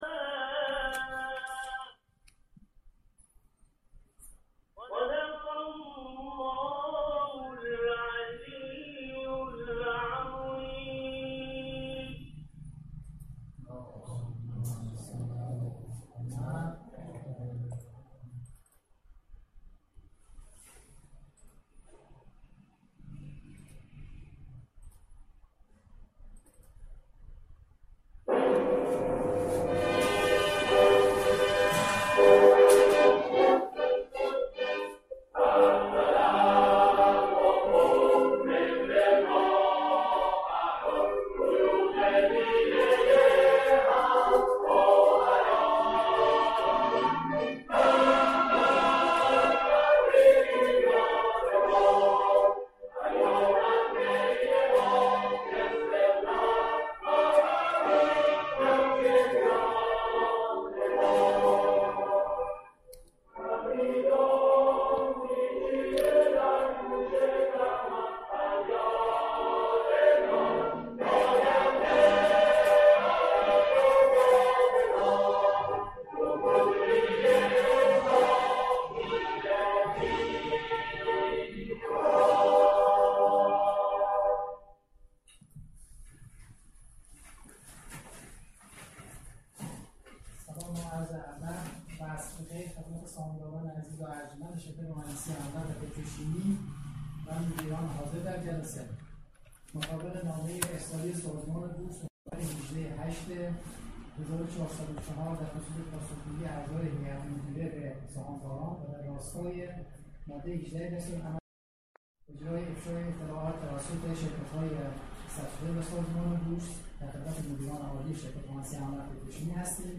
کنفرانس آنلاین پرسش و پاسخ سهامداران و مدیران شرکت مهندسی حمل و نقل پتروشیمی